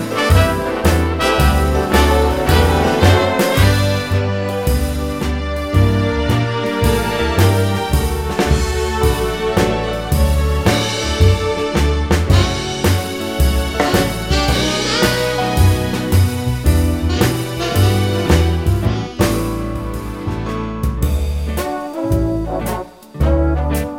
Minus Acoustic Guitar Jazz / Swing 3:25 Buy £1.50